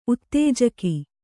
♪ uttējaka